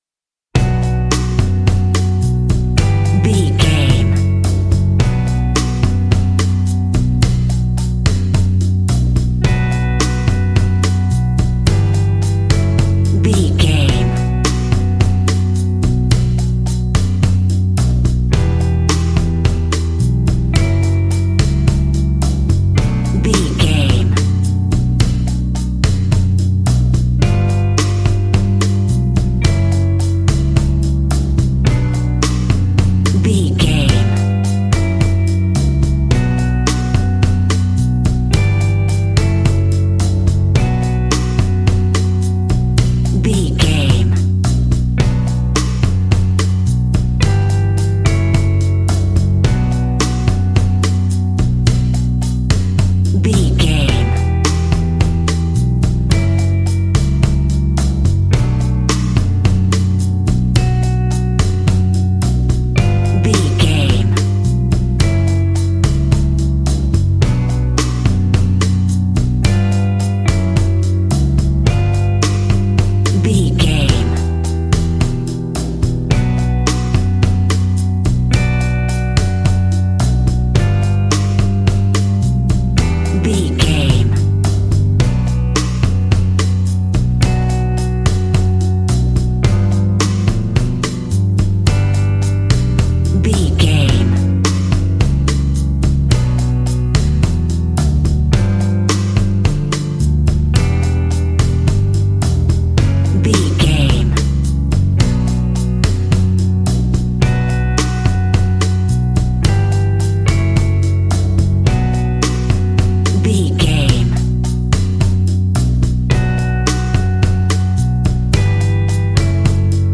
An exotic and colorful piece of Espanic and Latin music.
Aeolian/Minor
Slow
maracas
percussion spanish guitar
latin guitar